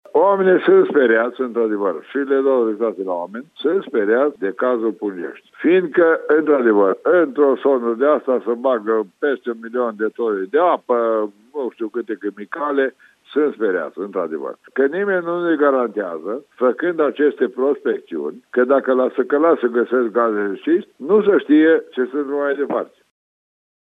Prin urmare se pot face prospecţiuni doar cu acordul autorităţilor, spune primarul Ilie Todaşcă.